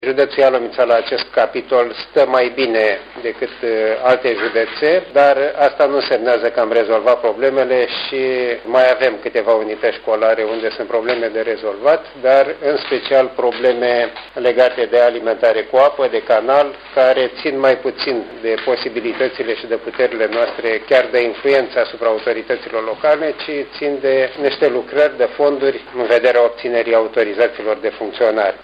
În opinia inspectorului general şcolar, situaţiile pot fi rezolvate cu investiţii prin autoritatea locală iar atragerea de fonduri cade în sarcina celor din fruntea localităţilor respective: